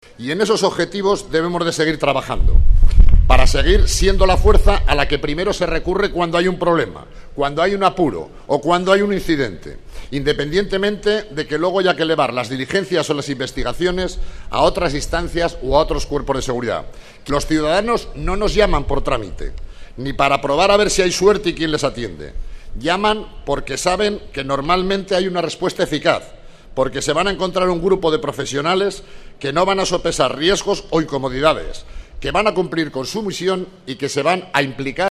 Las condecoraciones se han entregado en el transcurso de un sencillo acto, celebrado en la plaza de la Solidaridad del cuartel de Palafox, que ha servido también para recordar a los policías fallecidos y a los que se han jubilado.
Por su parte, el delegado de la Policía, Carlos Pérez Anadón, hacía hincapié en la singularidad del trabajo de polícía y en la conexión que tienen con los ciudadanos, ya que es la primera fuerza a la que se recurre cuando hay un problema.